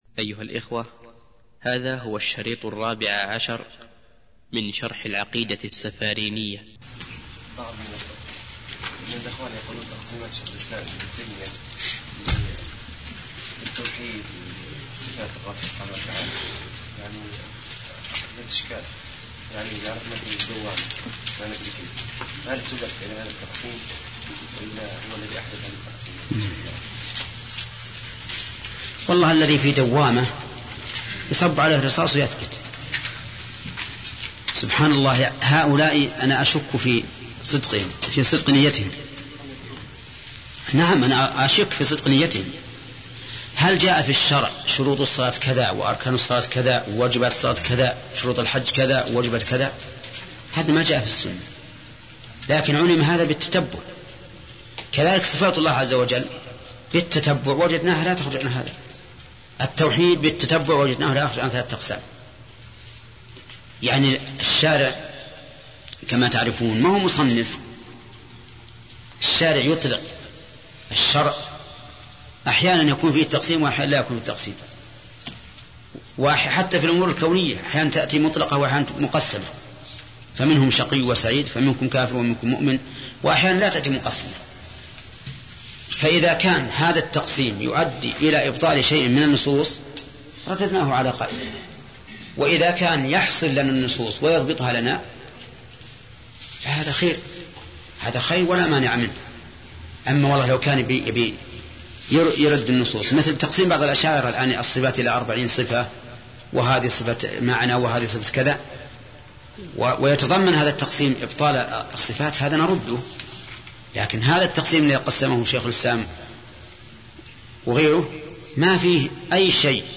الدرس الرابع عشر - فضيلة الشيخ محمد بن صالح العثيمين رحمه الله